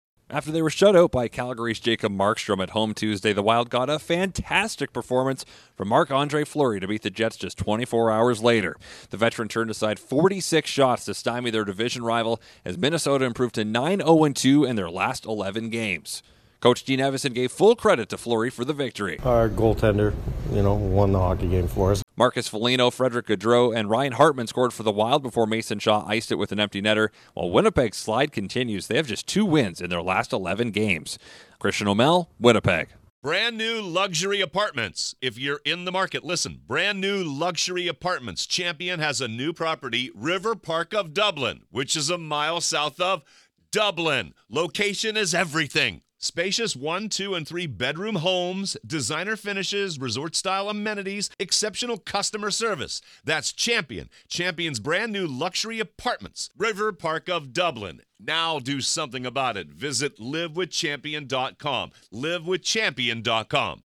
The Wild resume their hot stretch by beating the Jets. Correspondent